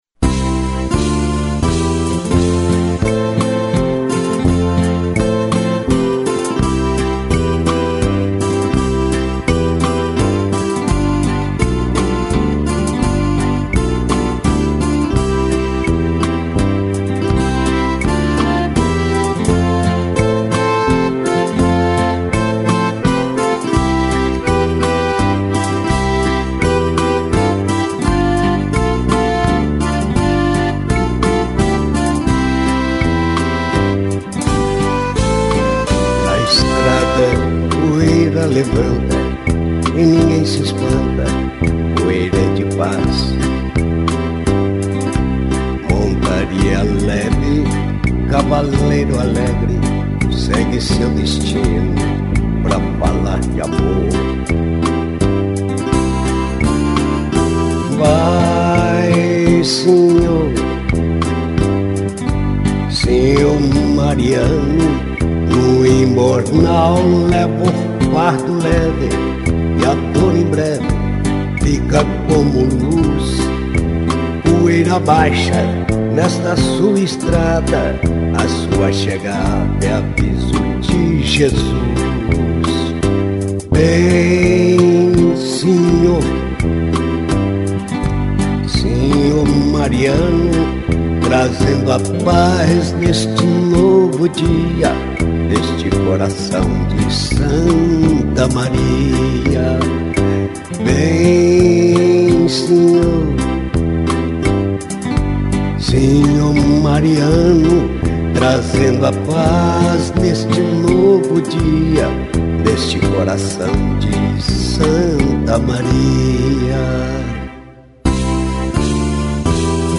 poema-canção